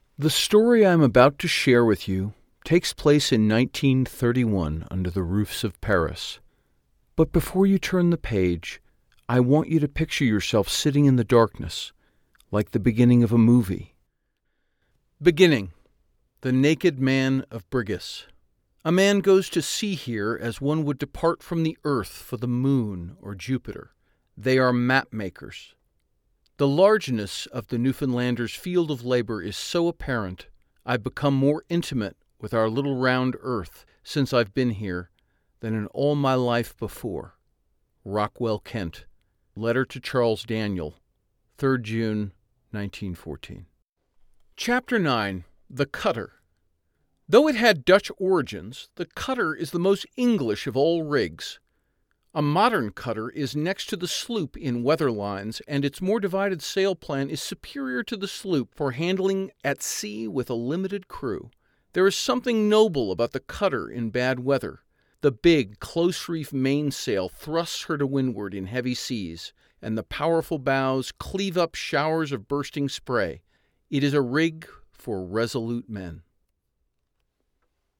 0621Dry_narrative_books.mp3